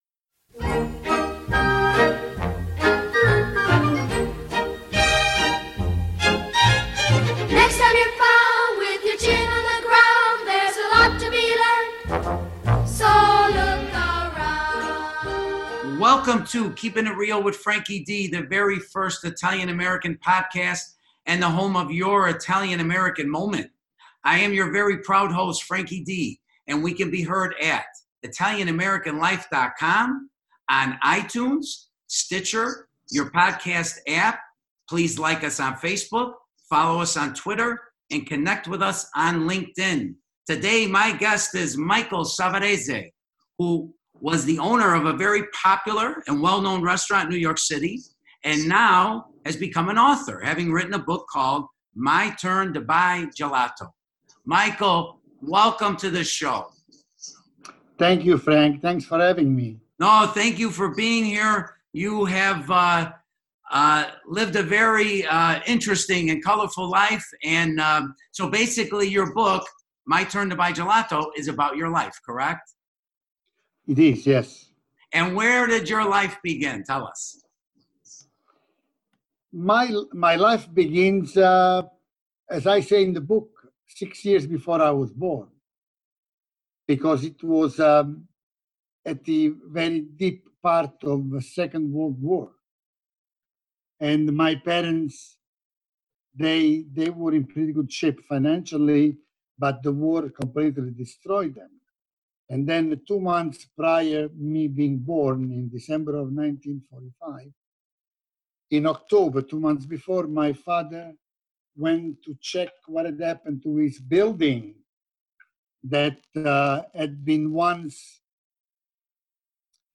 Interview with author and former restauranteur